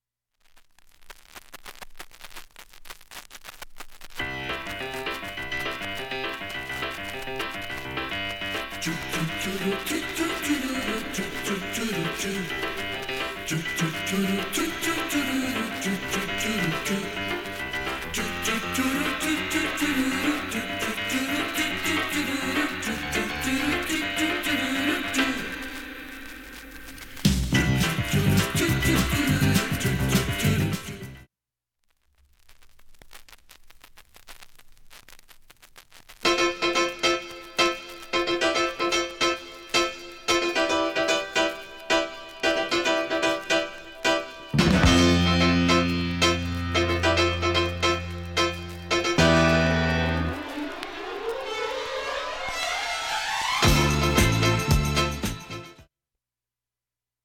SOUL、FUNK、JAZZのオリジナルアナログ盤専門店
瑕疵部分 なぜか両面始りの数秒イントロ部で サーフェスノイズが少し出ます。 B-1始めはかすかなレベルです。
胸が躍るディスコ大名曲B2